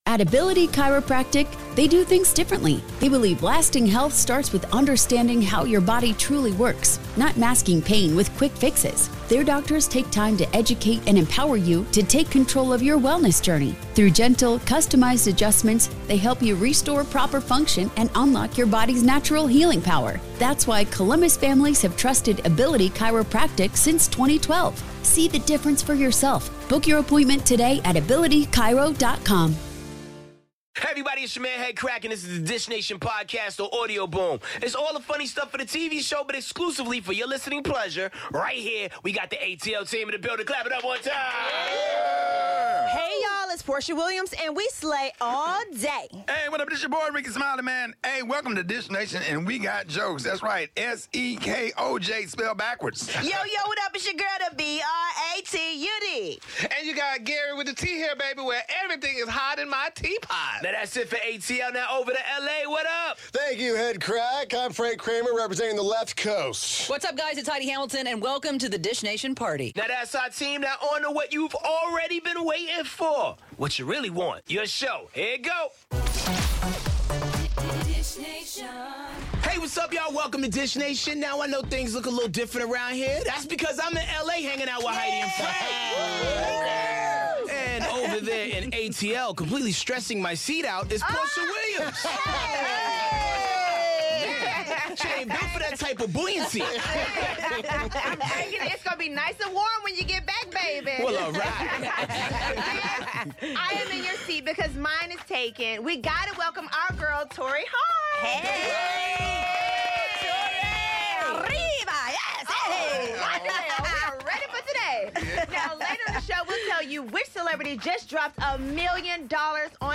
Guest host: Torrei Hart. Best moments from last night's 'Real Housewives of Atlanta' plus all the latest with Tyrese, Drake, Shemar Moore, Blake Shelton, Rita Ora, Jared Leto, Mark Wahlberg and more.